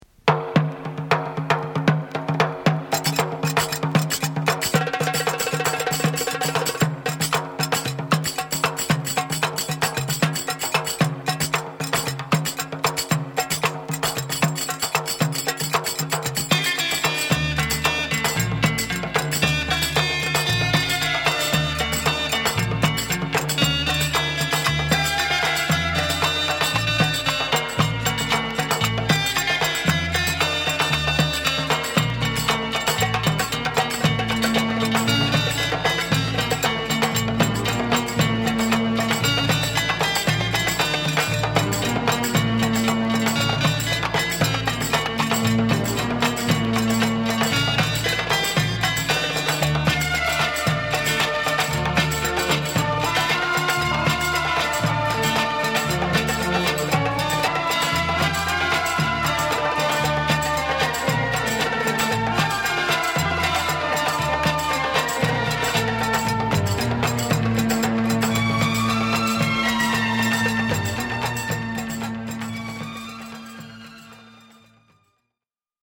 レバノン産レア・グルーヴ ベリーダンス 試聴 LP
複雑に乱打するパーカッションや妖艶なオルガンの電子グルーヴが重なる